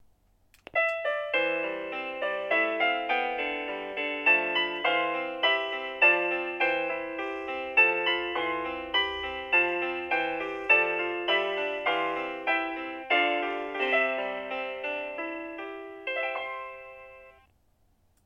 • 36 vyzváněcí tónů k výběru, ukázky zvonění: